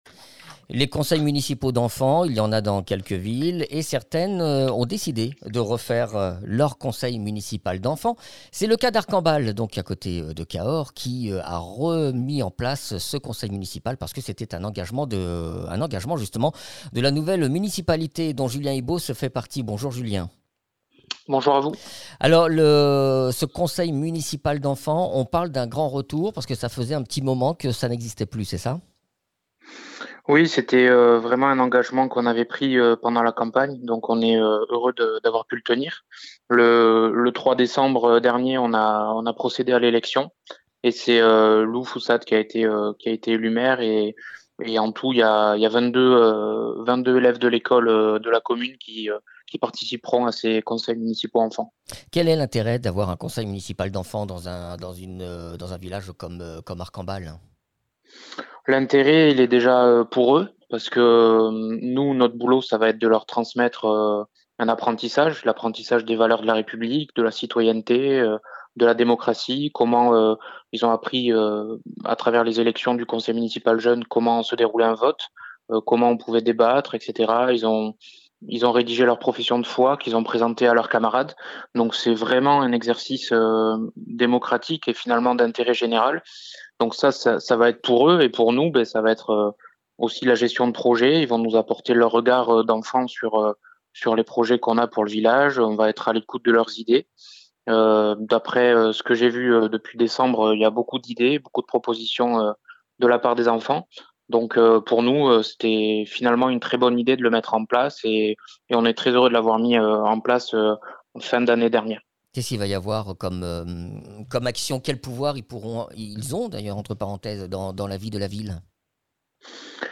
Interviews
Invité(s) : Julien Ibos, élu à la municipalité d’arcambal